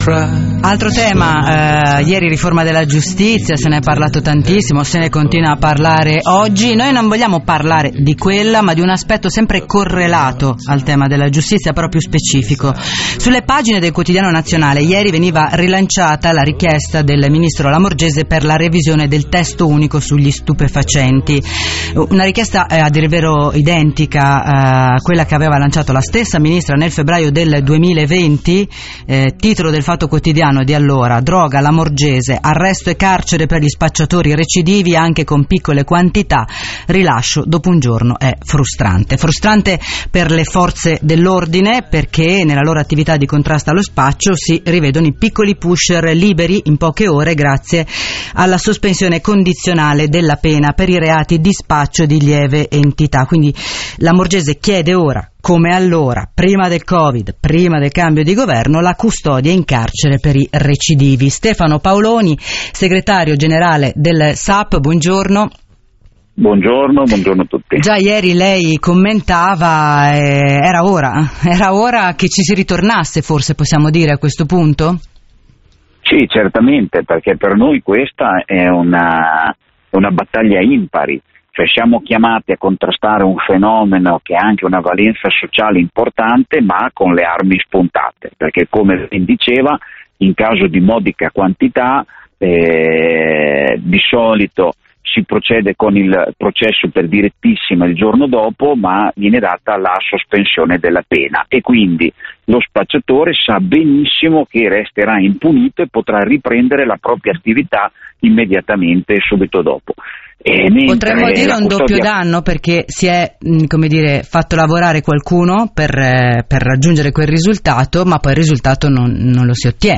AUDIO INTERVISTA RADIO 24